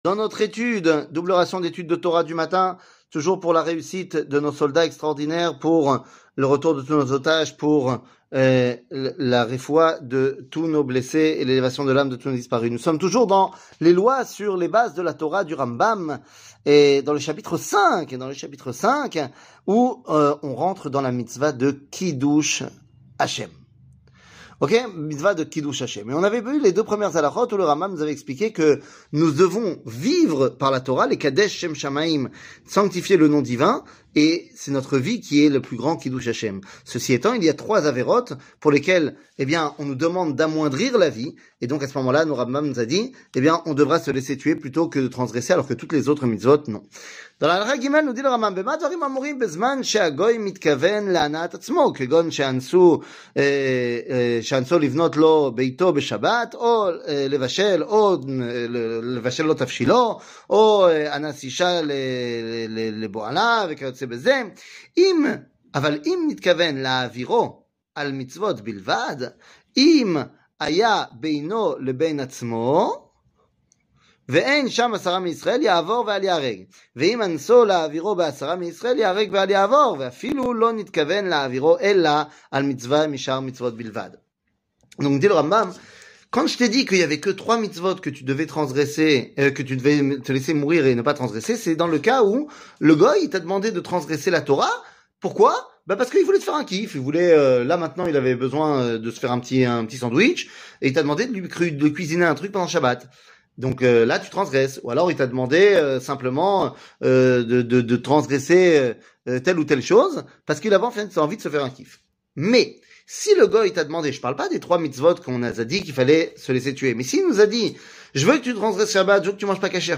שיעור מ 05 מרץ 2024
שיעורים קצרים